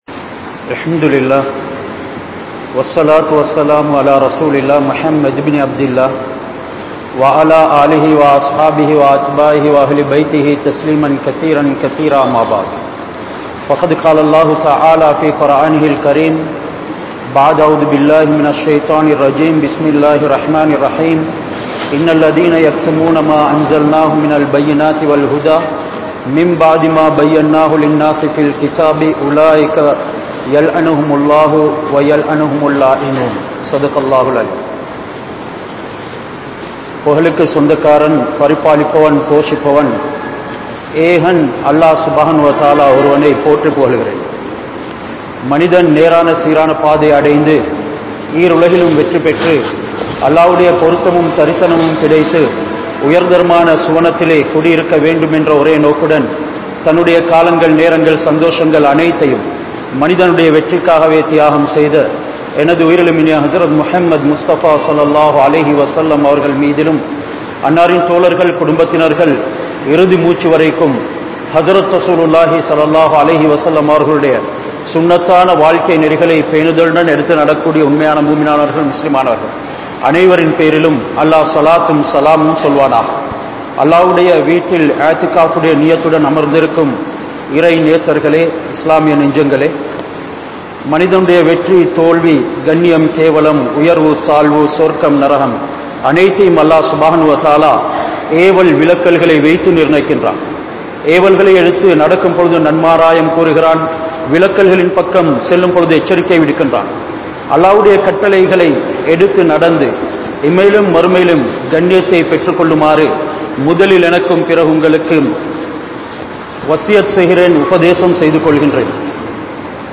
Mattravarhalai Safikkaatheerhal (மற்றவர்களை சபிக்காதீர்கள்) | Audio Bayans | All Ceylon Muslim Youth Community | Addalaichenai
Kandy, Line Jumua Masjith